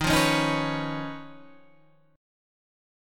D#7sus2#5 Chord